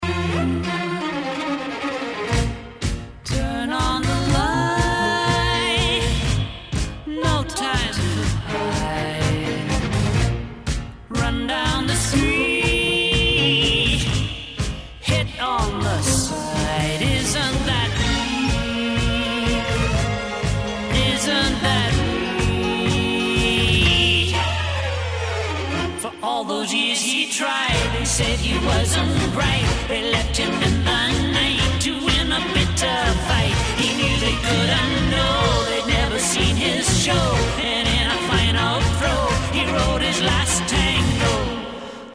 Recorded at Hérouville & Ramport Studios, London